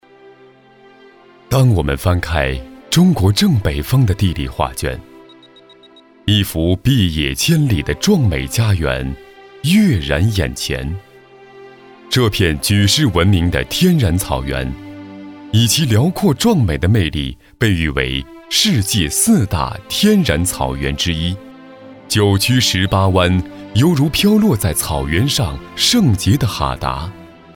标签： 稳重
配音风格： 科技 稳重 浑厚 活力 激情 讲述 时尚